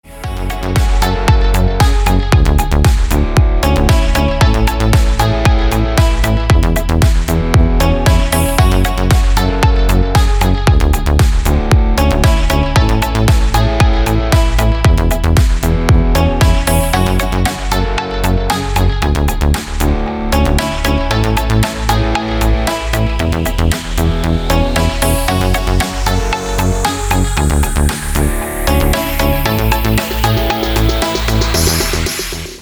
Клубный отрывок на вызов